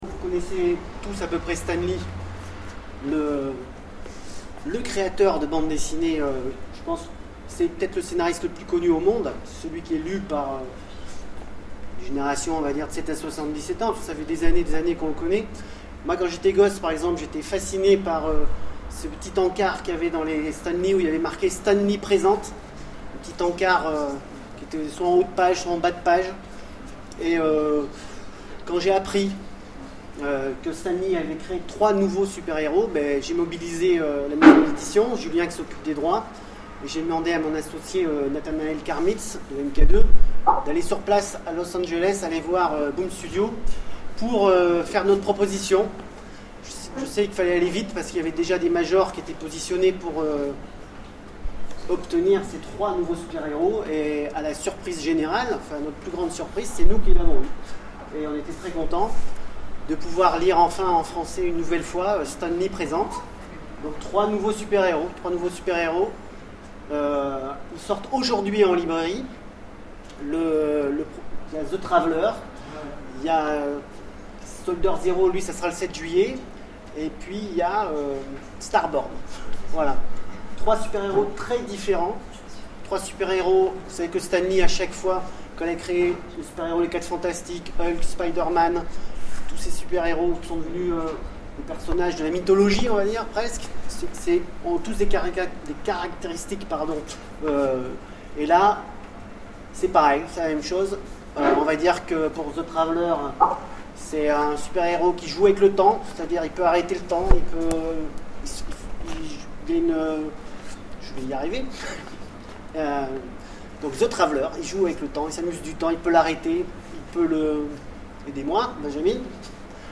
Voici l'enregistrement de l'interview